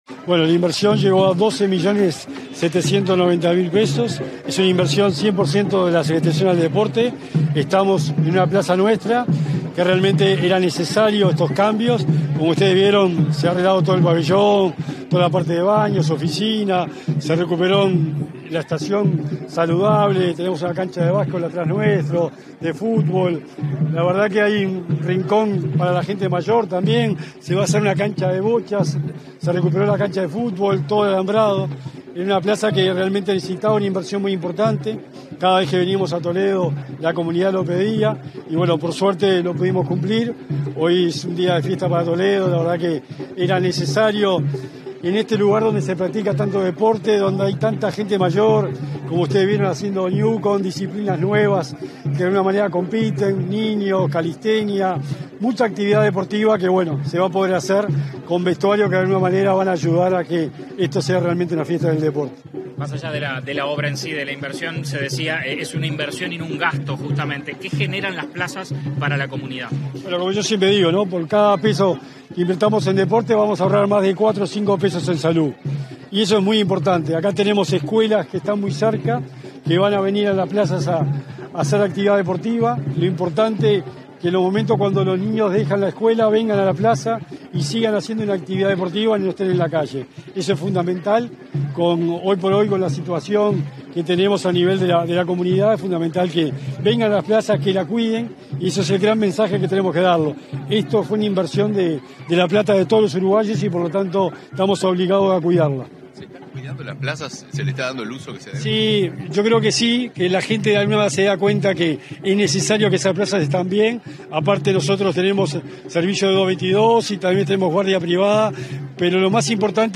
Declaraciones del secretario nacional del Deporte, Sebastián Bauzá
Declaraciones del secretario nacional del Deporte, Sebastián Bauzá 14/10/2024 Compartir Facebook X Copiar enlace WhatsApp LinkedIn Tras la inauguración de obras en la plaza de deportes de Toledo, este 14 de octubre, el secretario nacional del Deporte, Sebastián Bauzá, dialogó con los medios informativos presentes.
bauza prensa.mp3